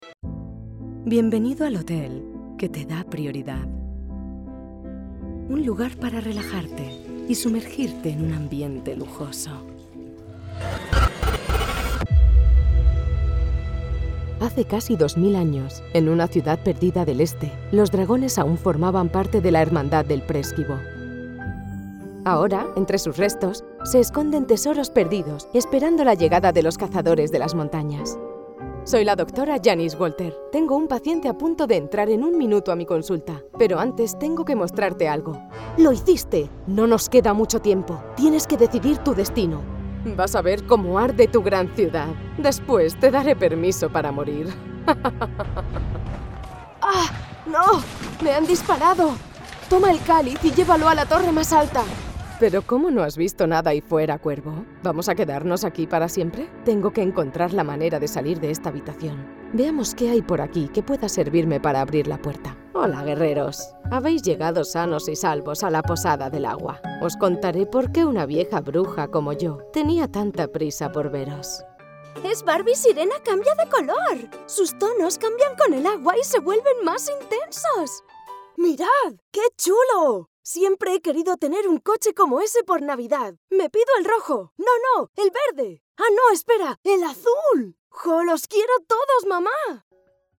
Video Games
European Spanish female voice over talent at your disposal!
I have a current, relatable, on trend voice, that is bright, clear, informative and engaging.
Iberian Spanish accent. 🙂